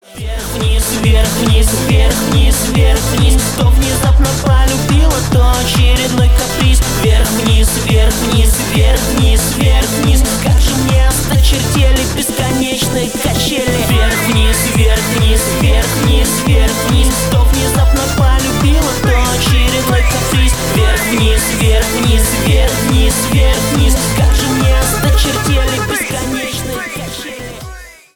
Поп Музыка
ритмичные